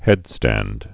(hĕdstănd)